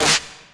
Original creative-commons licensed sounds for DJ's and music producers, recorded with high quality studio microphones.
Loudest frequency: 166Hz Nineties Trip-Hop Snare Drum Sound D# Key 112.wav .WAV .MP3 .OGG 0:00 / 0:01 Royality free snare sound tuned to the D# note.
nineties-trip-hop-snare-drum-sound-d-sharp-key-112-3FZ.wav